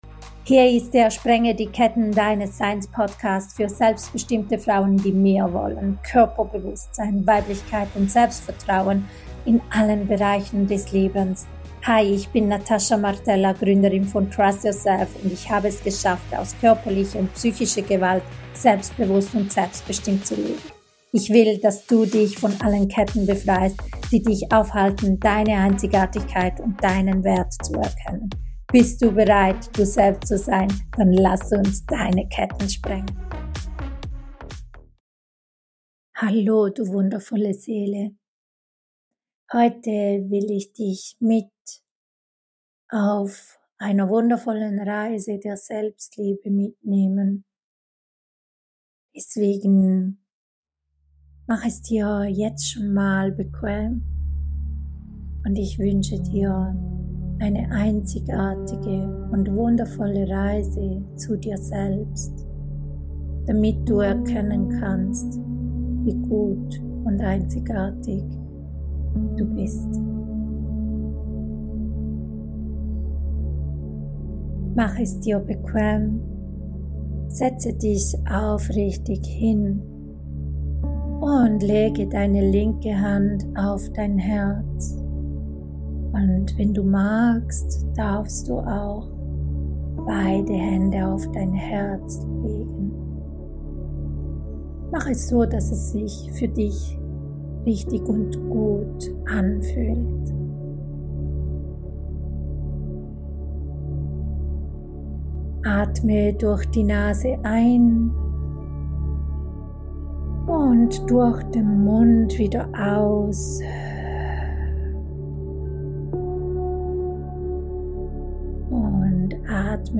Diese geführte Meditation hilft dir, innere Ruhe zu finden und deine Selbstwahrnehmung zu stärken. Wir konzentrieren uns darauf, negative Gedanken loszulassen und eine liebevolle Beziehung zu uns selbst aufzubauen.